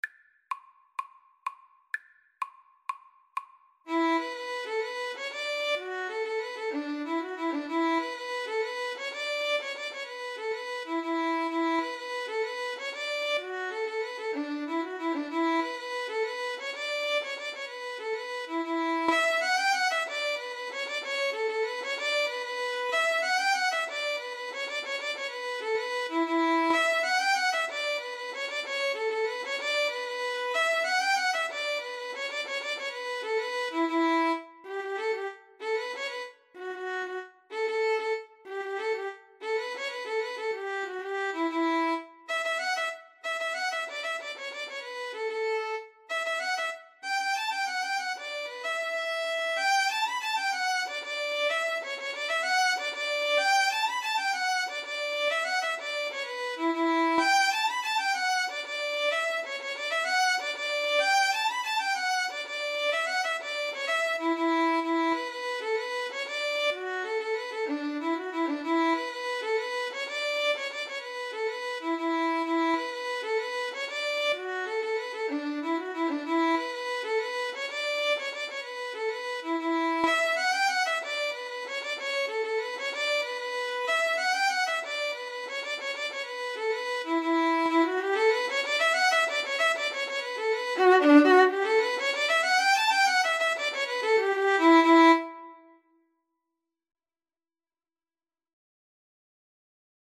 Free Sheet music for Violin-Guitar Duet
D major (Sounding Pitch) (View more D major Music for Violin-Guitar Duet )
Fast .=c.126
12/8 (View more 12/8 Music)
Irish